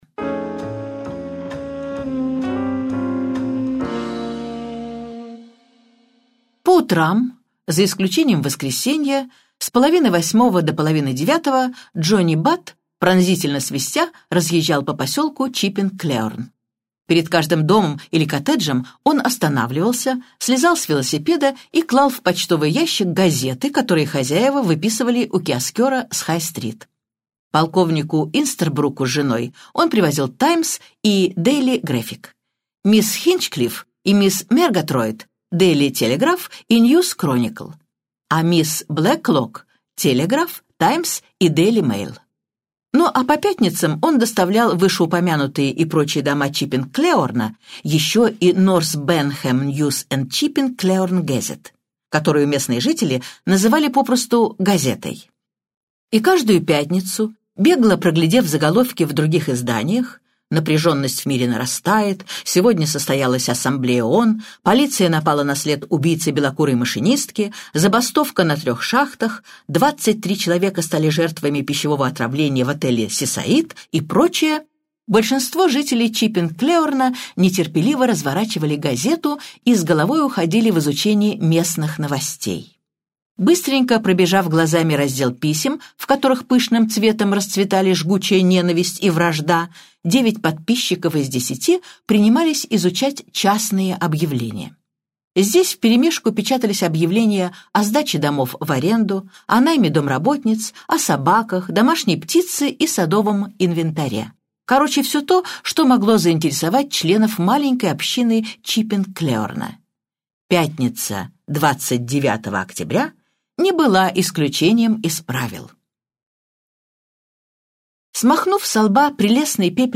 Аудиокнига Объявлено убийство - купить, скачать и слушать онлайн | КнигоПоиск